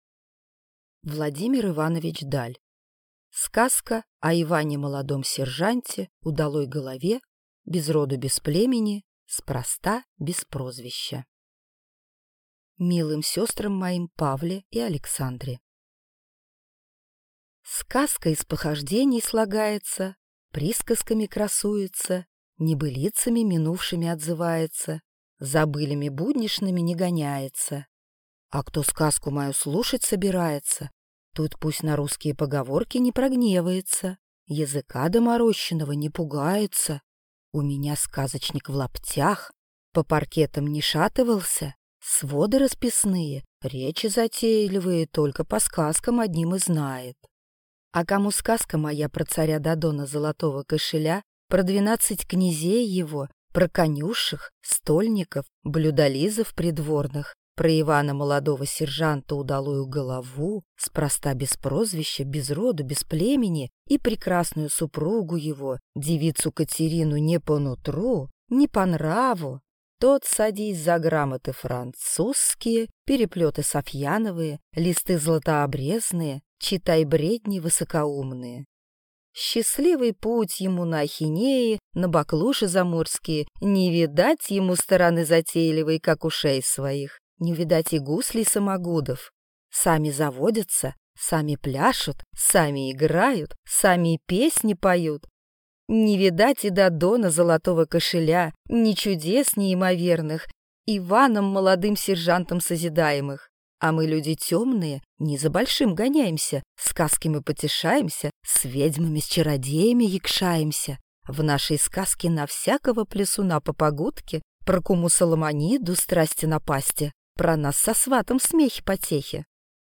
Аудиокнига Сказка о Иване Молодом сержанте Удалой голове, без роду, без племени, спроста без прозвища | Библиотека аудиокниг